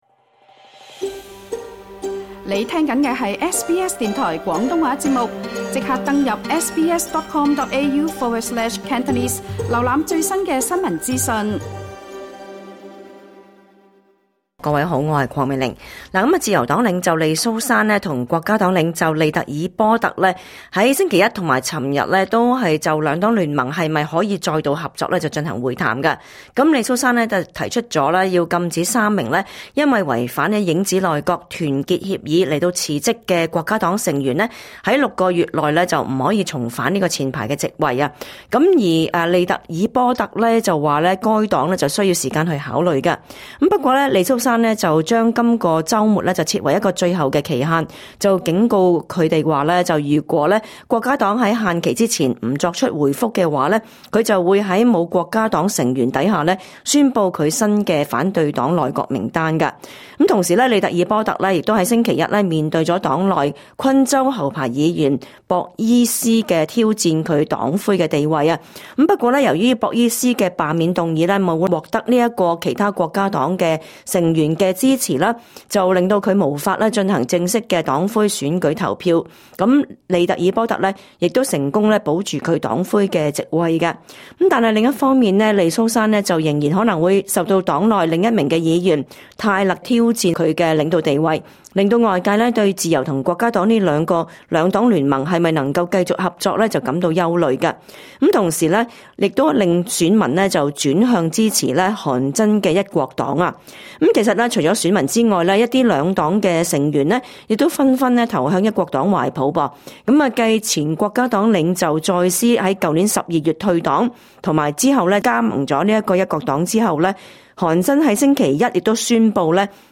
*本節目內嘉賓及聽眾意見並不代表本台立場，而所提供的資訊亦只可以用作參考，個別實際情況需要親自向有關方面查詢為準。